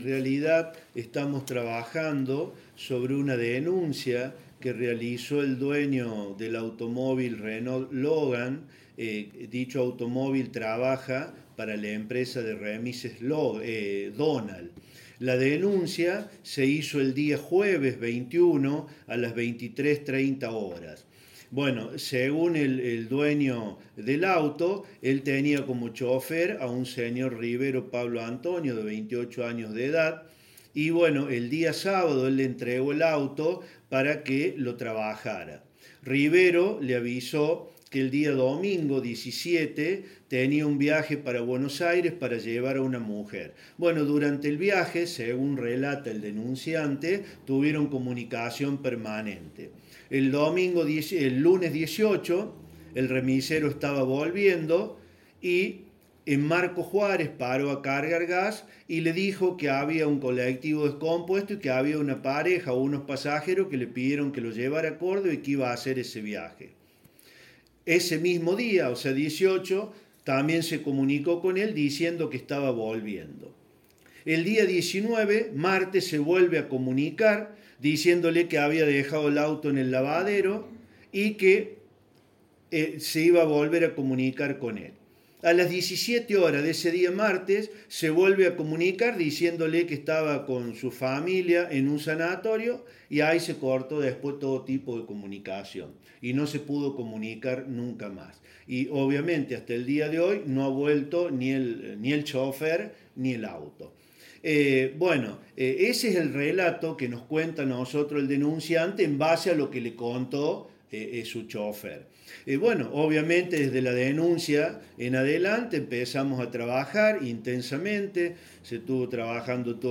TAXISTA DESAPARECIDO: HABLÓ EL FISCAL BOSIO.
En relación al hecho el fiscal Rene Bosio decía lo siguiente: